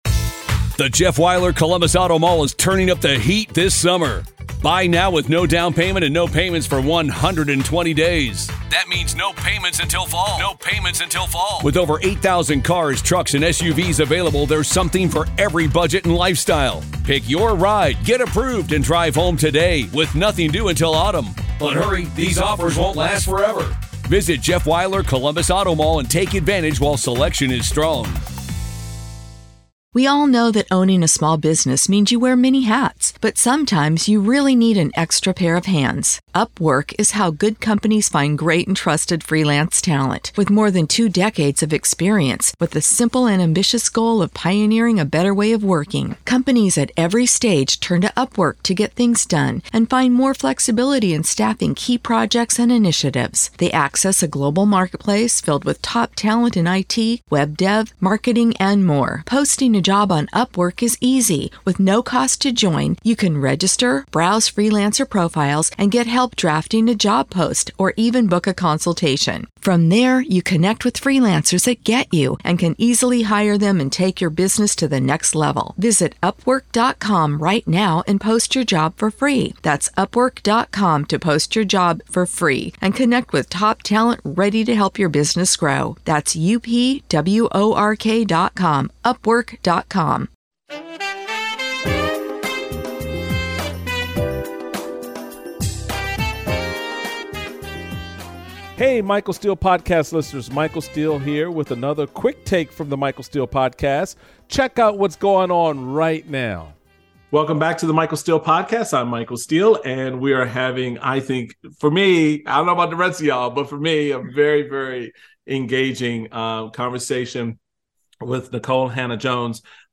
Michael Steele speaks with Pulitzer Prize-winning journalist Nikole Hannah-Jones about The 1619 Project, what motivated her to write it, its purpose and its pushback.